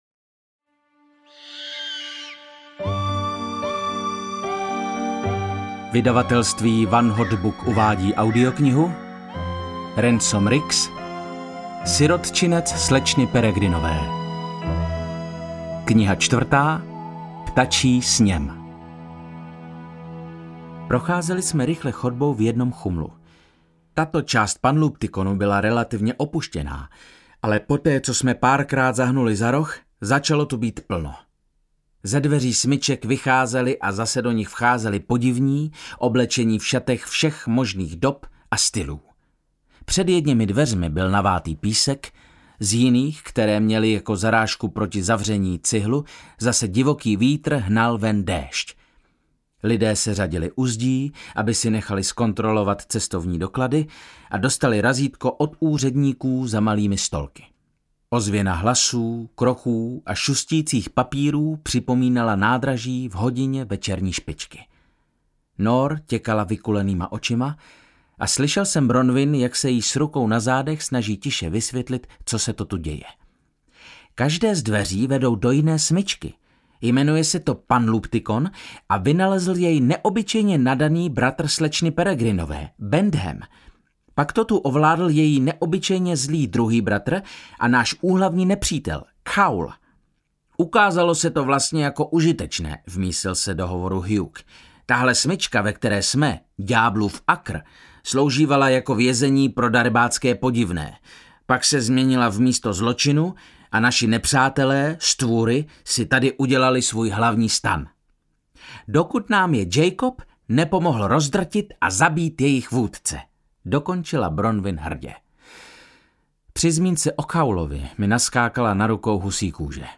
Audio knihaSirotčinec slečny Peregrinové: Ptačí sněm
Ukázka z knihy
• InterpretViktor Dvořák
sirotcinec-slecny-peregrinove-ptaci-snem-audiokniha